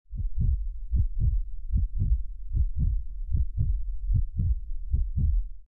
heartbeat.wav